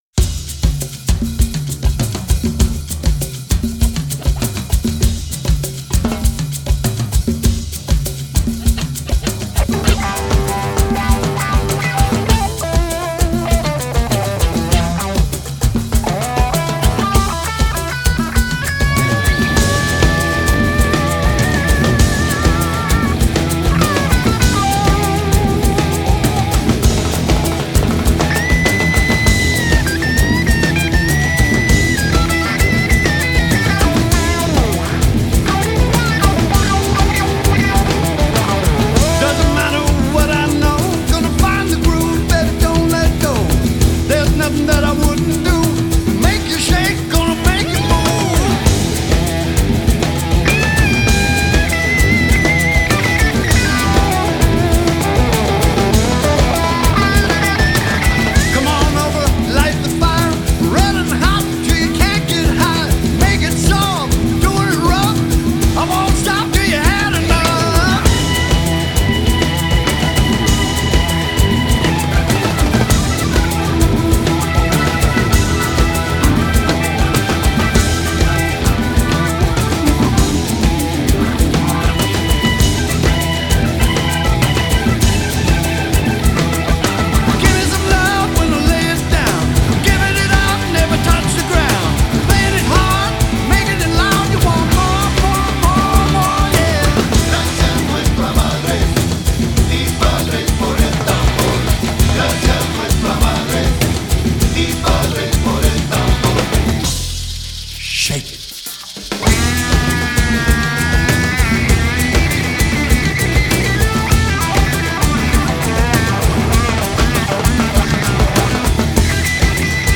Genre: Classic Rock, Blues Rock